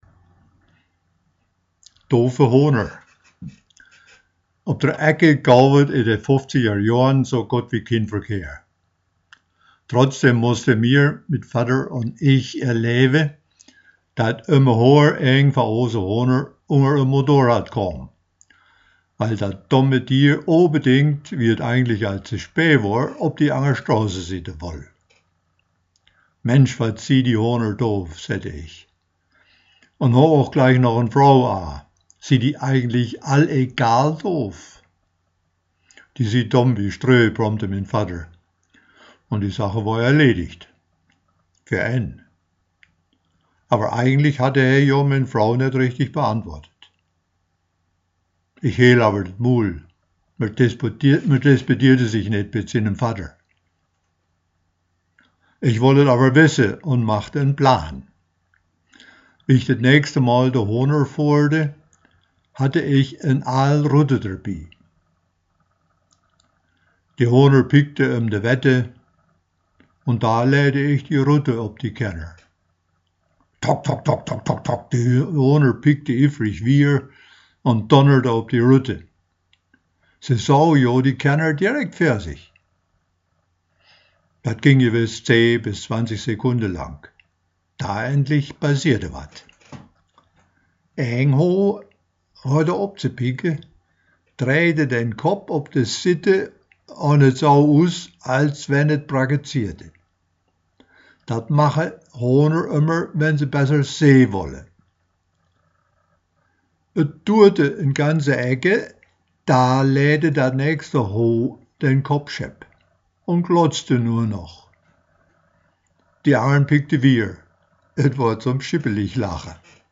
• DOOFE HOHNER – Hörbuch zum Beitrag im Dorfgespräch Ausgabe 14